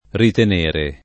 ritenere [ riten % re ] v.; ritengo [ rit $jg o ]